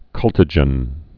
(kŭltə-jən)